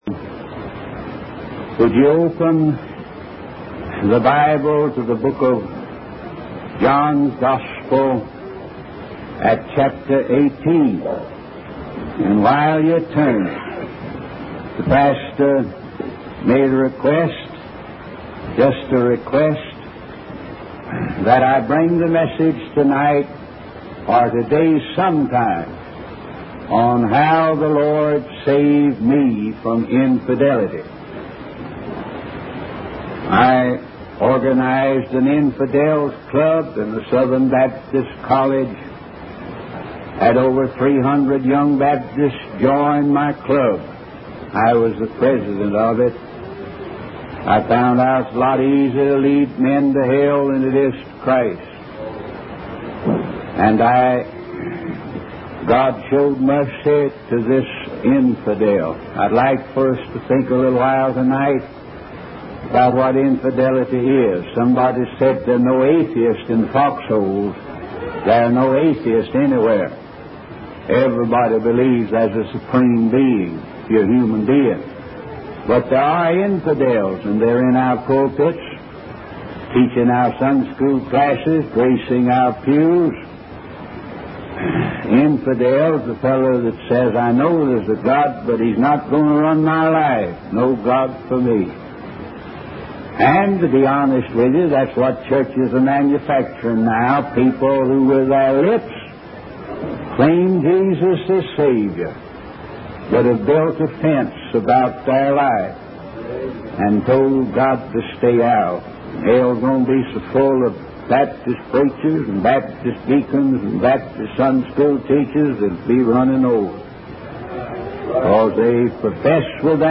In this sermon, the preacher discusses the responsibility placed on Jesus Christ to save his own and judge everyone else. He emphasizes the importance of demonstrating the power of God rather than just talking about it, drawing a parallel to the effectiveness of television advertisements.